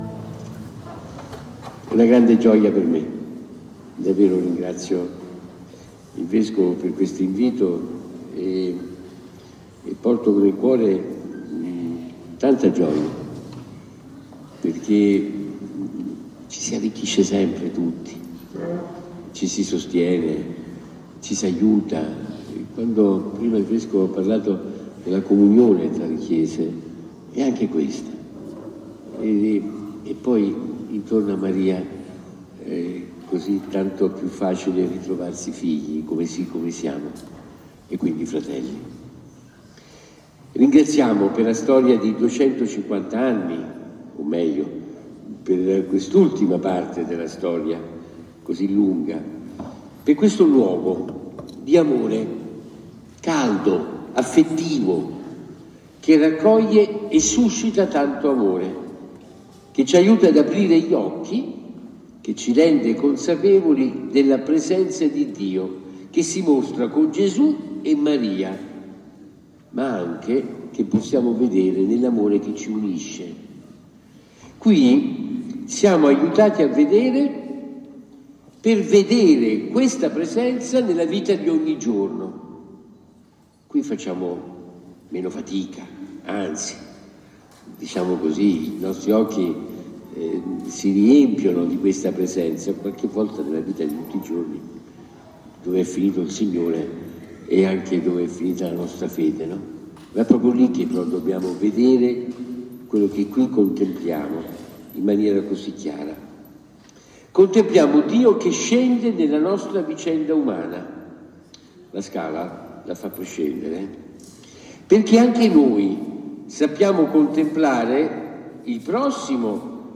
Omelia del Card. Matteo Zuppi durante la Santa Messa presso il Santuario di Maria SS. della Scala
Omelia-Card.-Zuppi-25-4-26.mp3